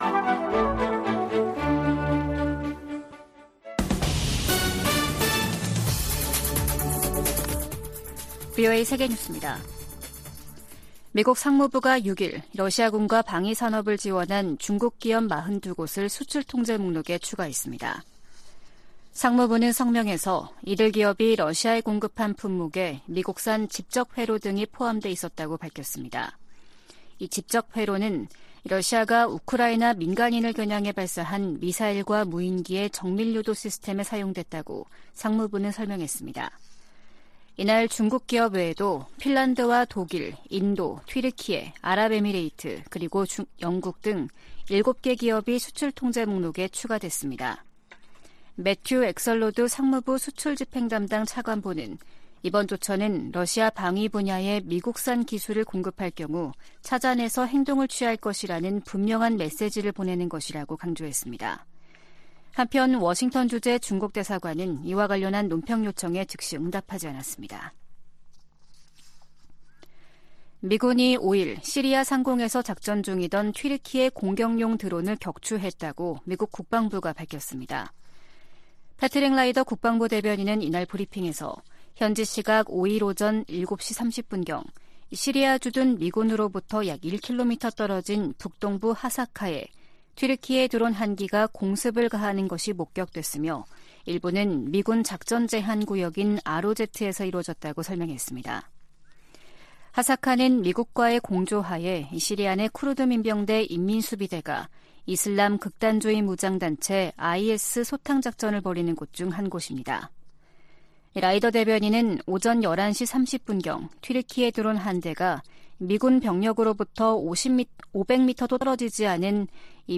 VOA 한국어 아침 뉴스 프로그램 '워싱턴 뉴스 광장' 2023년 10월 7일 방송입니다. 미 국방부는 최근 공개한 대량살상무기(WMD) 대응 전략을 정치·군사적 도발로 규정한 북한의 반발을 일축했습니다. 미국의 인도태평양 지역 동맹과 파트너십이 그 어느 때보다 강화되고 있으며, 미국의 가장 큰 전략적 이점 중 하나라고 미국 국방차관보가 말했습니다. 미 상원의원들이 올해 첫 한반도 안보 청문회에서 대북 정책을 실패로 규정하며 변화 필요성을 강조했습니다.